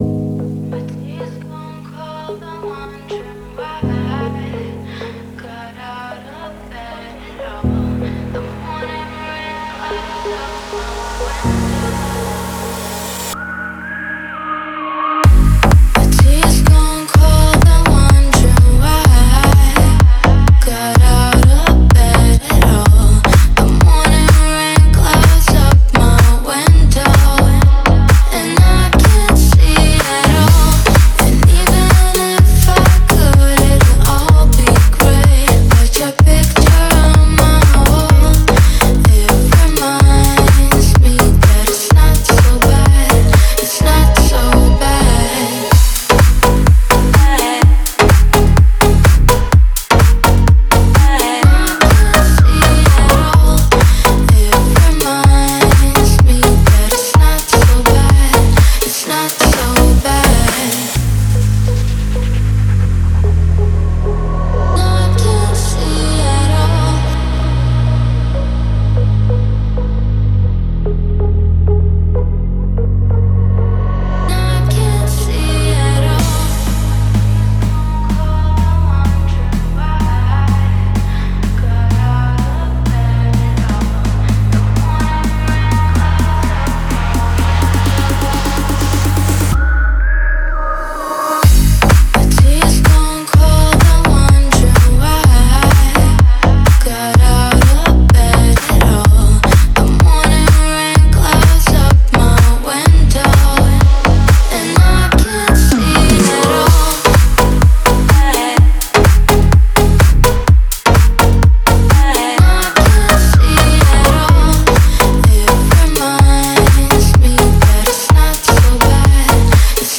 это энергичная электронная танцевальная композиция
Зажигательные биты и мелодичный вокал